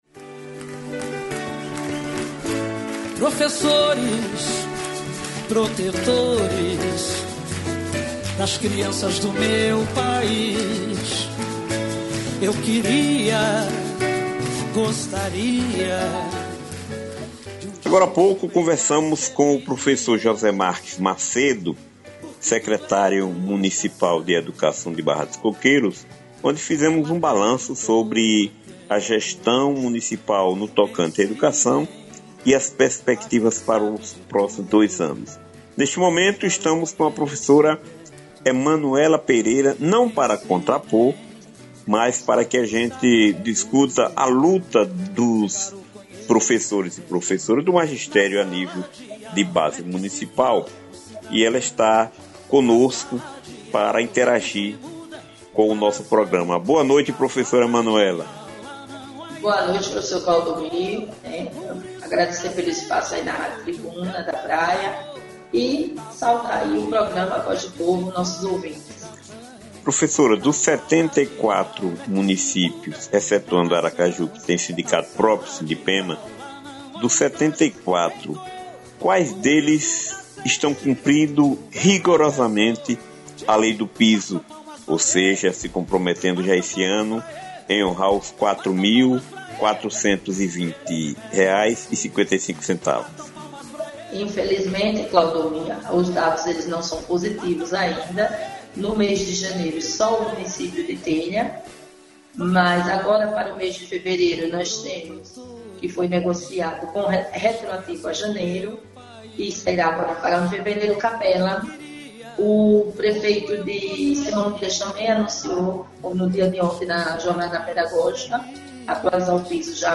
A sindicalista fez um panorama das lutas no interior pelo cumprimento do Piso do Magistério e pela valorização dos profissionais da educação Confira abaixo o áudio da entrevista disponibilizada pela emissora: